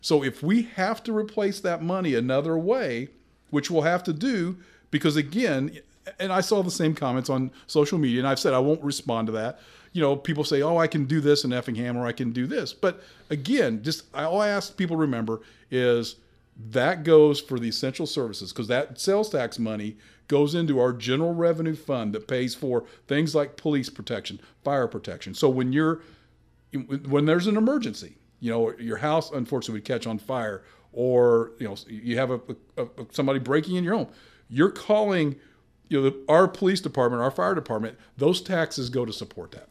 And, Mayor Knebel points out the 1% grocery sales tax goes directly into the general revenue funds.